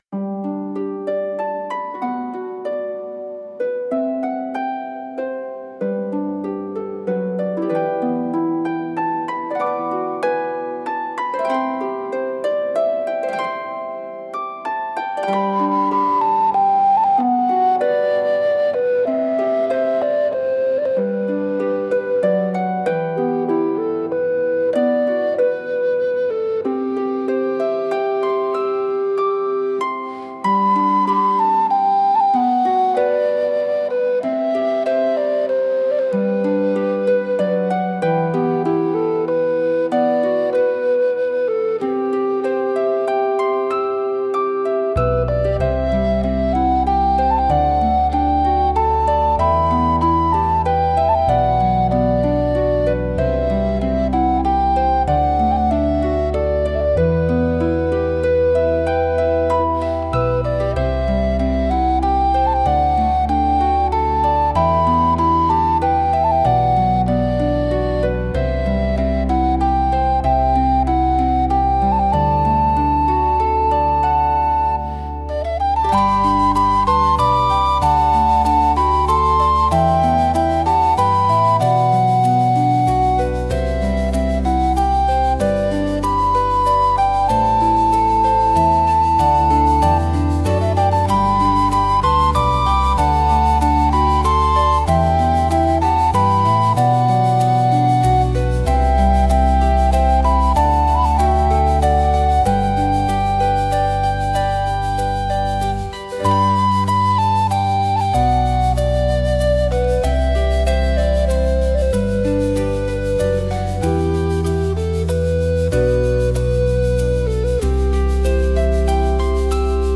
穏やか